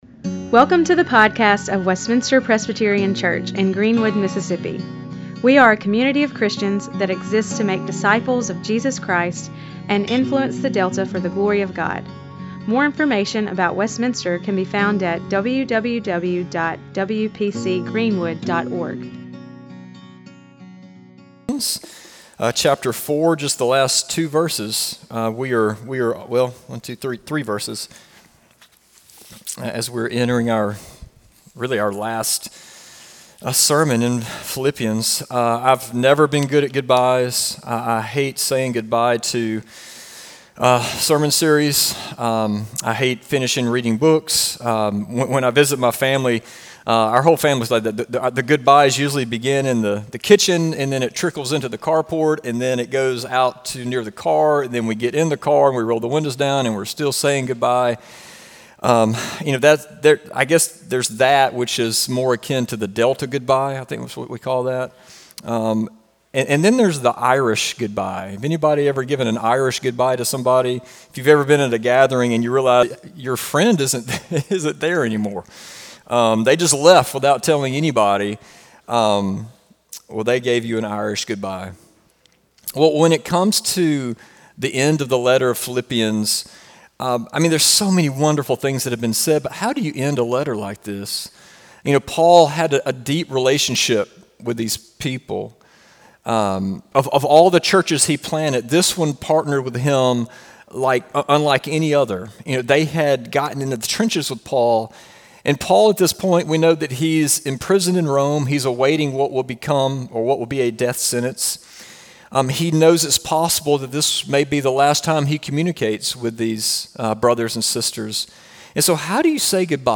Sermons
sermon-3-9-25.mp3